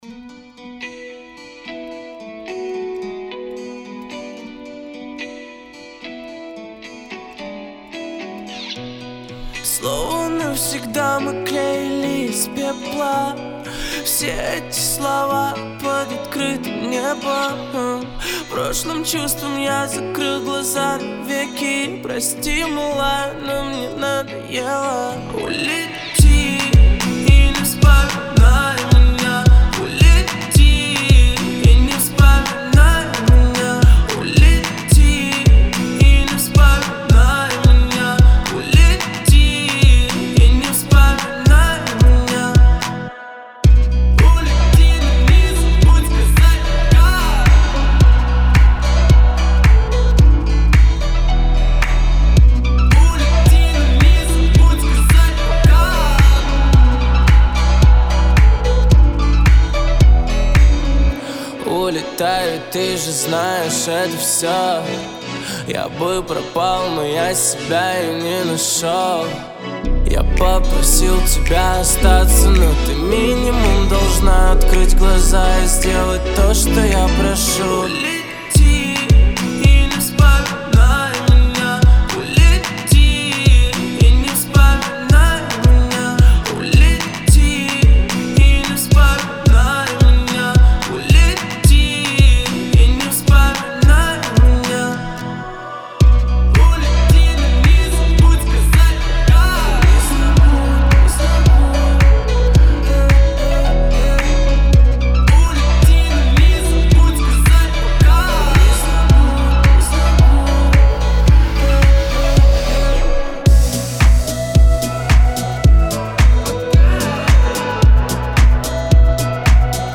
Жанр: Популярная музыка